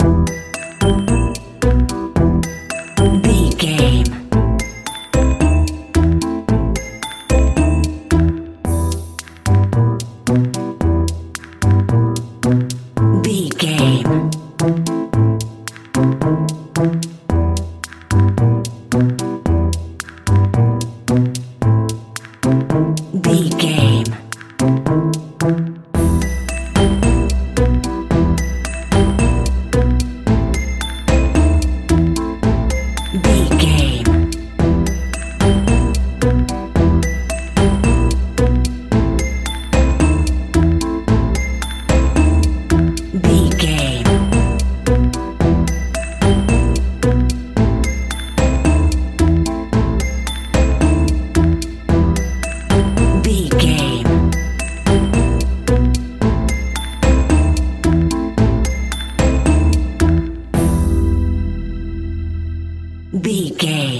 Aeolian/Minor
scary
ominous
haunting
eerie
piano
drums
synthesizer
spooky
horror music